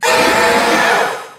basculegion_ambient.ogg